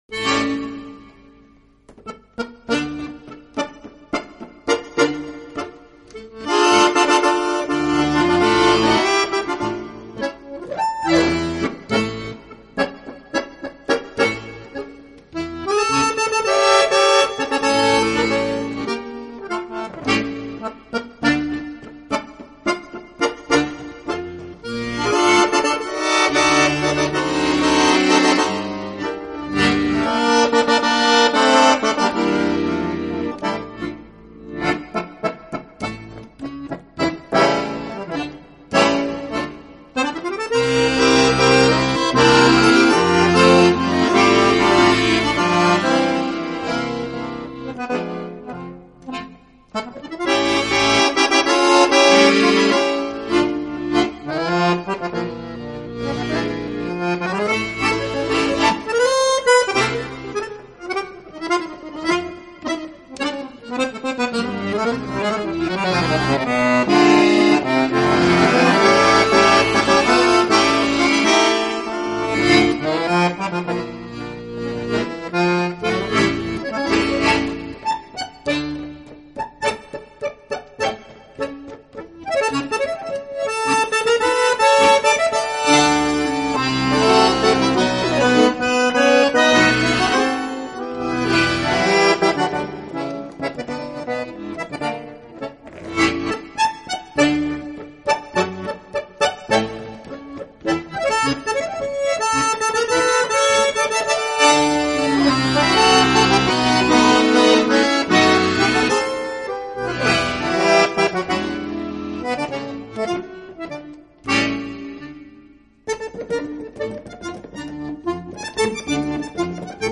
【手风琴】
首曲子里，主题旋律就像回忆，挥之不去、不断提醒，而那旋律，是哀伤而凄迷的！
手风琴的音色也有独特的寂寞感，好像在遥远遥远的过去里，有着难以忘却但又极为